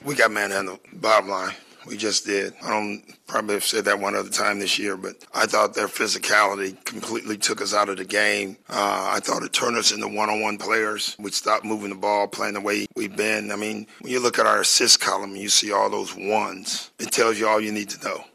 Bucks coach Doc Rivers talked about the loss.